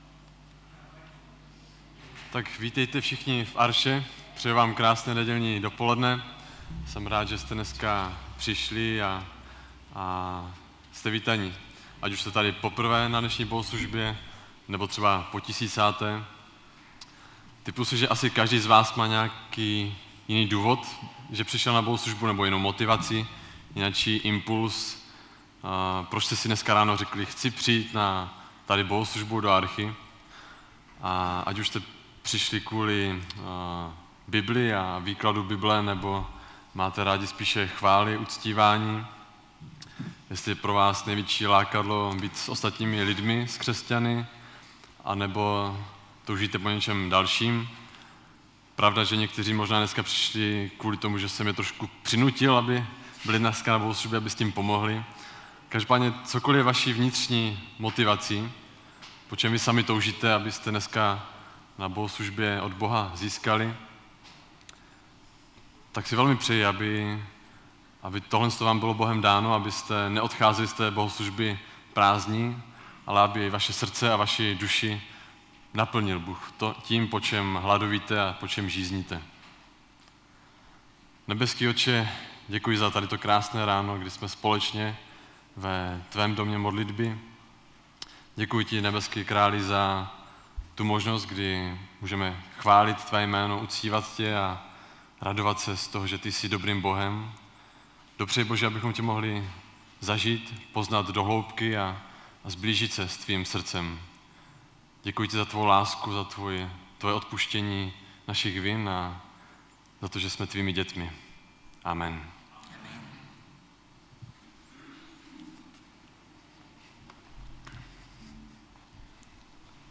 Mládežnická bohoslužba